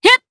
DarkFrey-Vox_Attack3_jp_b.wav